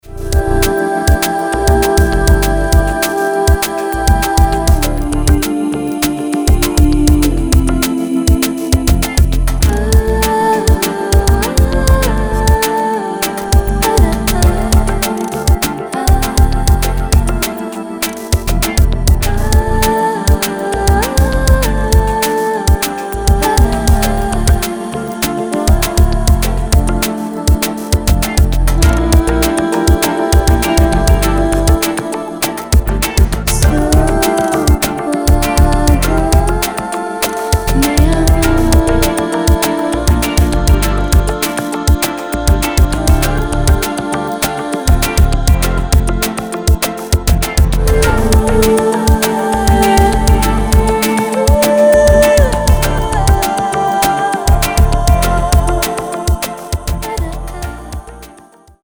Filed under Downtempo, Productions · Tagged with